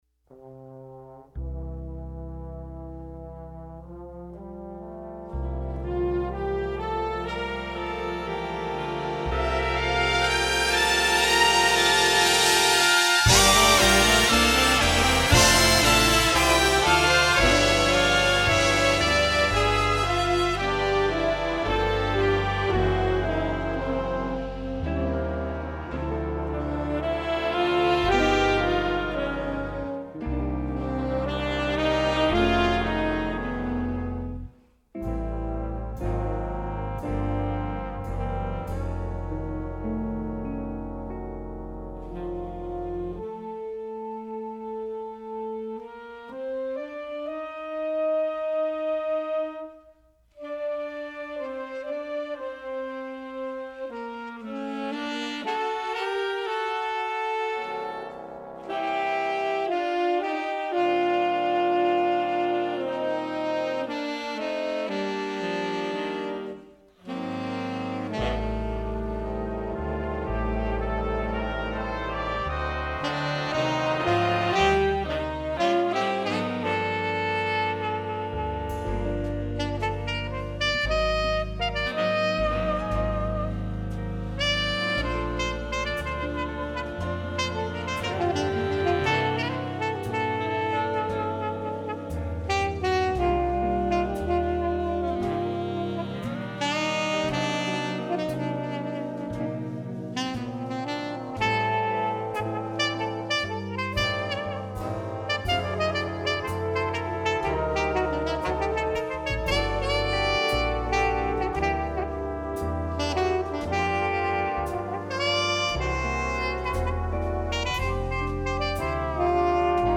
sax 1st 3rd 2nd 4th 5th
trp 1st 2nd 3rd 4th
trb 1st 2nd 3rd 4th
rythm Guitar Piano Bass Drums